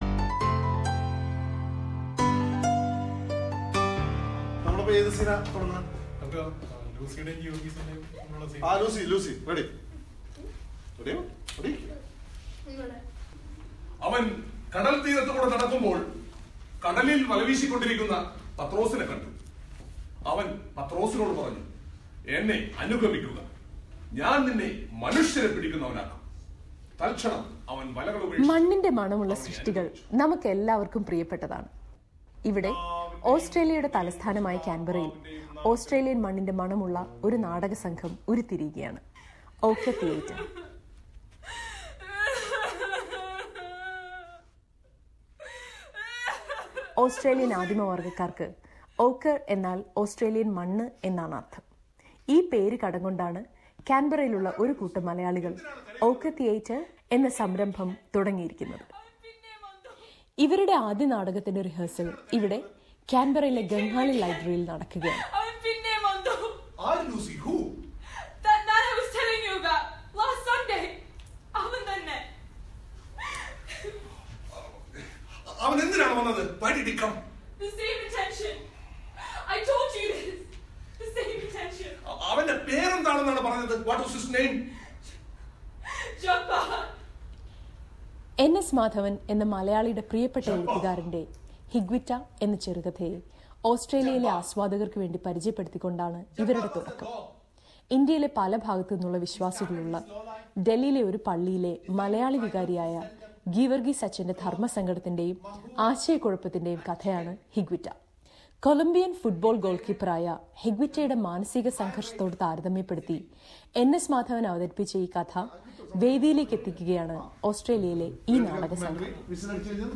Listen to a report about the theatre group and their first play.